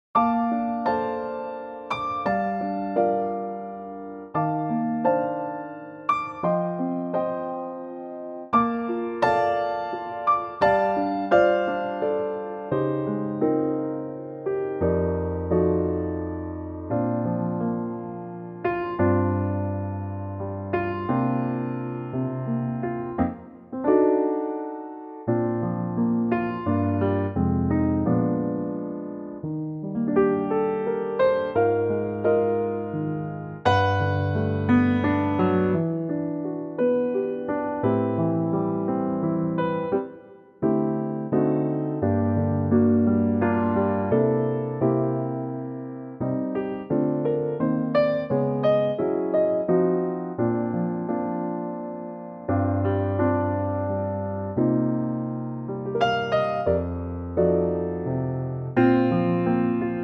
Unique Backing Tracks
key Bb 3:40
key - Bb - vocal range - G to D (huge range)